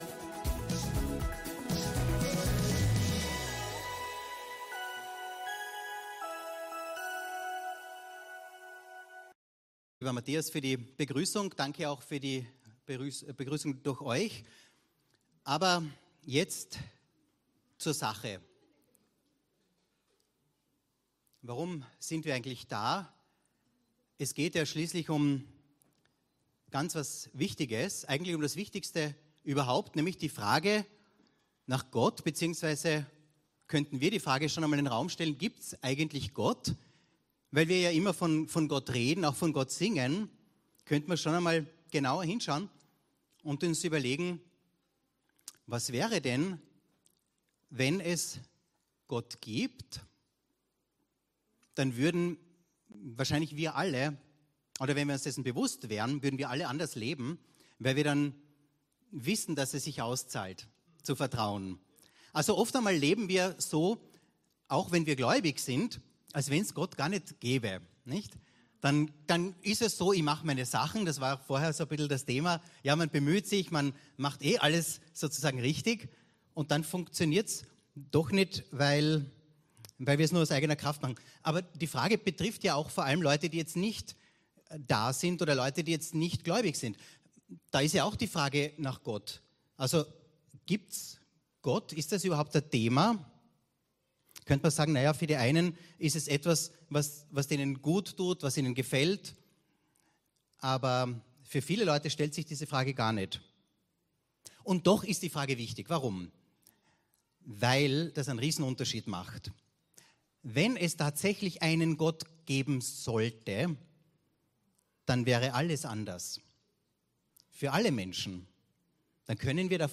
Gottesdienst aus der LIFE Church Wien.